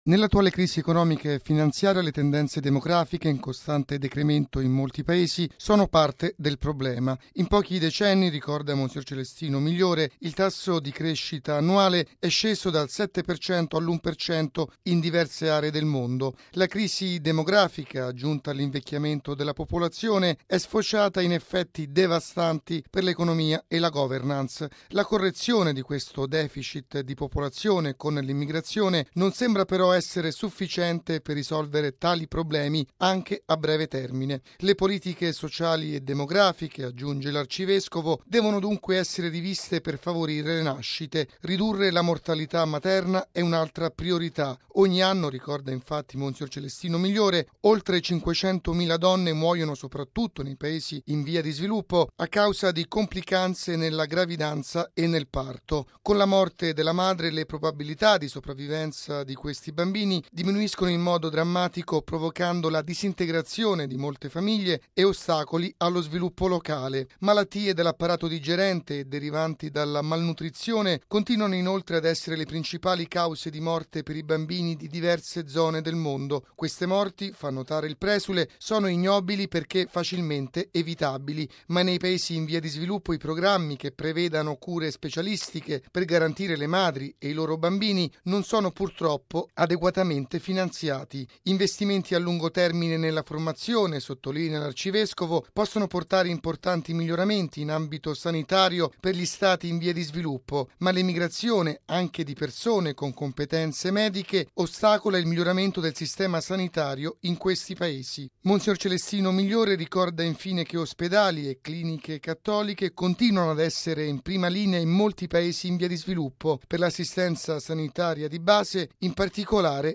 ◊   Si devono moltiplicare gli sforzi per salvaguardare la salute delle donne e dei bambini nei Paesi in via di sviluppo arginando i drammi della malnutrizione e della mortalità materna. E’ quanto ha affermato l’arcivescovo Celestino Migliore, osservatore permanente della Santa Sede all’Onu, intervenendo ieri a New York alla 43.ma Commissione sulla popolazione e lo sviluppo.